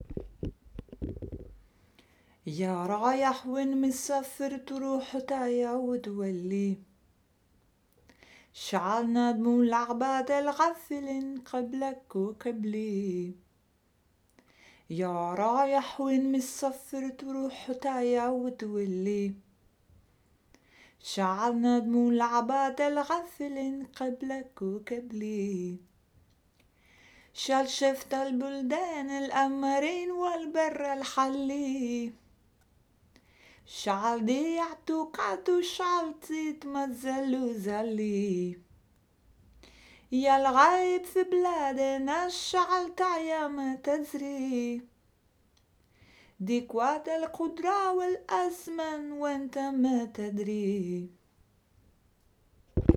voice
percussion